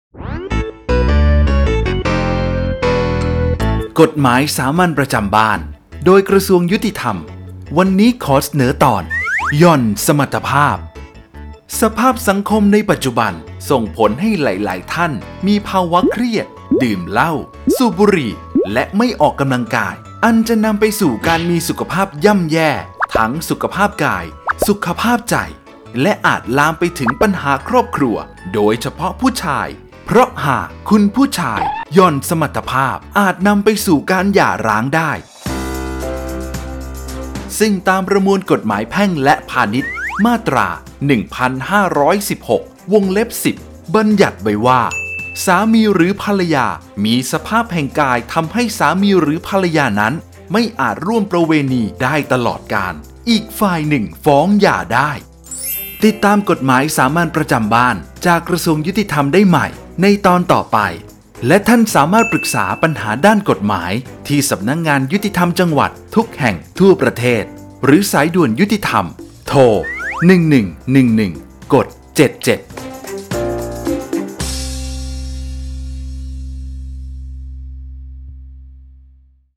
กฎหมายสามัญประจำบ้าน ฉบับภาษาท้องถิ่น ภาคกลาง ตอนหย่อนสมรรถภาพ
ลักษณะของสื่อ :   บรรยาย, คลิปเสียง